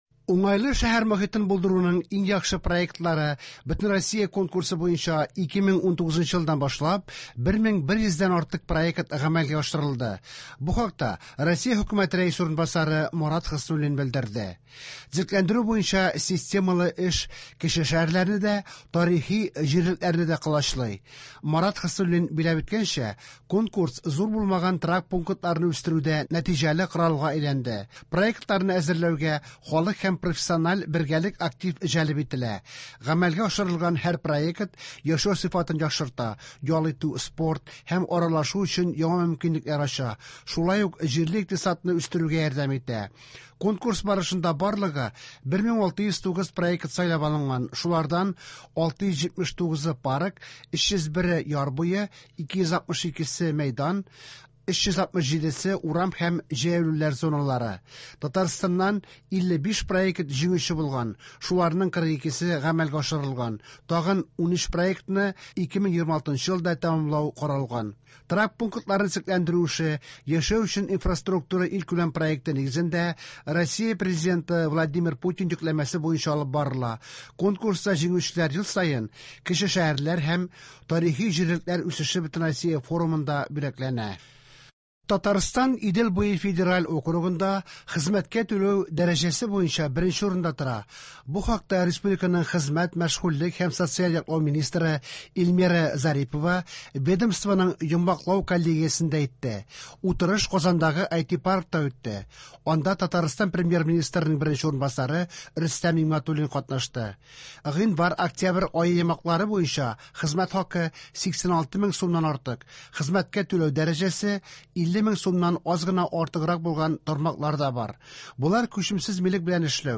Яңалыклар (16.01.26)